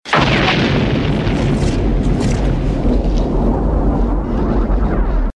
BIG EXPLOSION WITH ELECTRICITY CRACKLES.mp3
Original creative-commons licensed sounds for DJ's and music producers, recorded with high quality studio microphones.
big_explosion_with_electricity_crackles_2cw.ogg